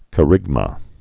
(kə-rĭgmə)